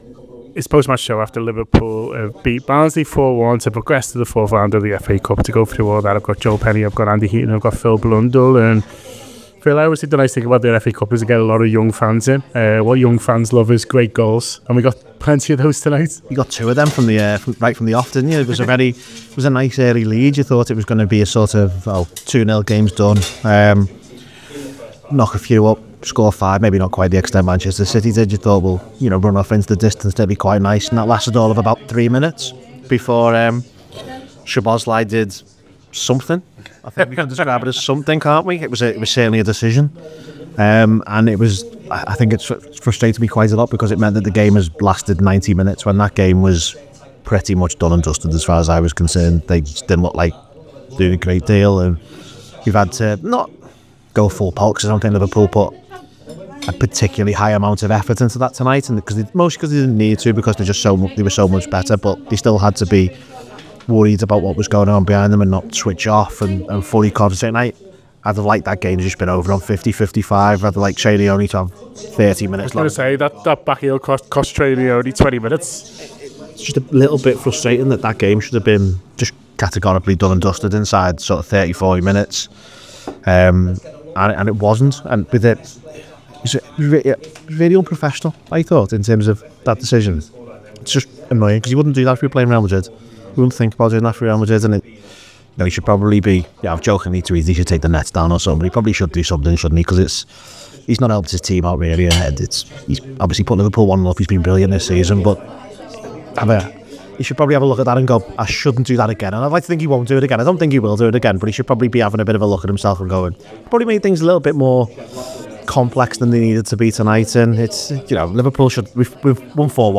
Below is a clip from the show – subscribe to The Anfield Wrap for more reaction to Liverpool 4 Accrington Stanley 0…